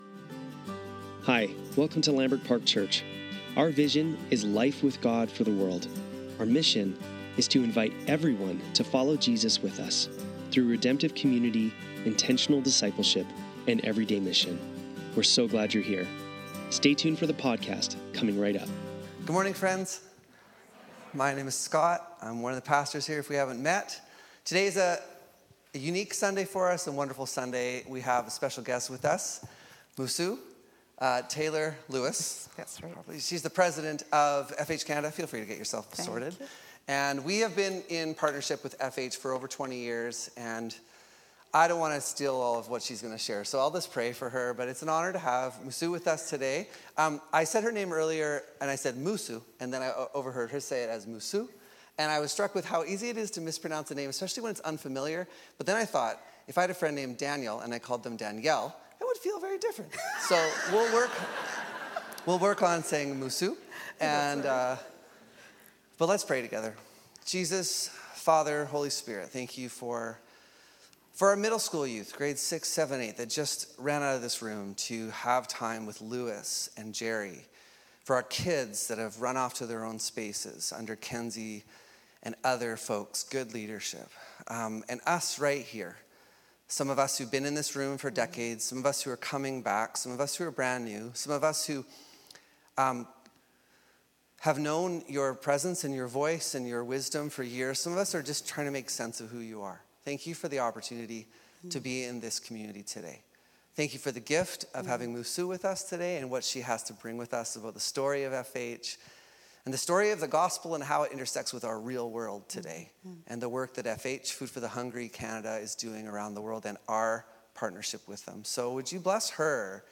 Sunday Service - September 21, 2025